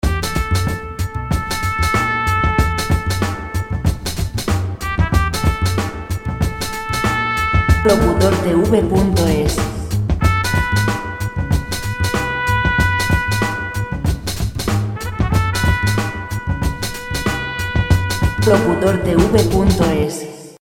musica sin copyright jazz, jazz free music